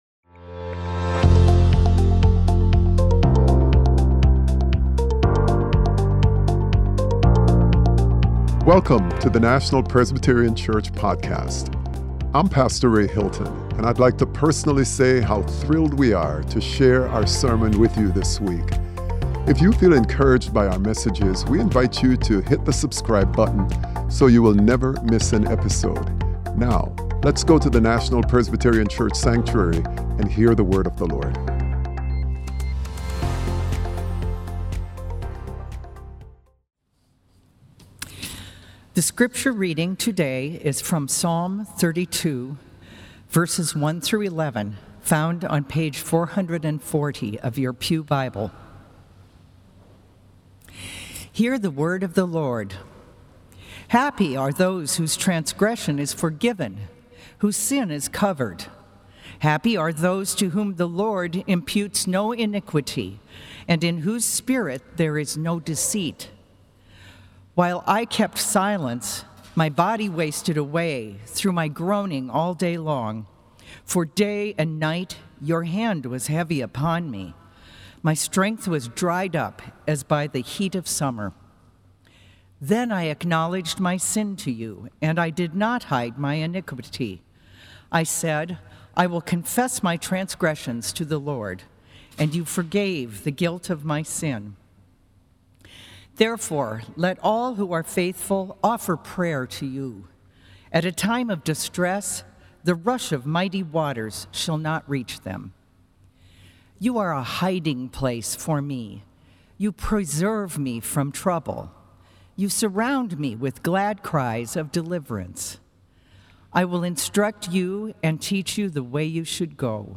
Sermon - Confession is Good for the Soul - National Presbyterian Church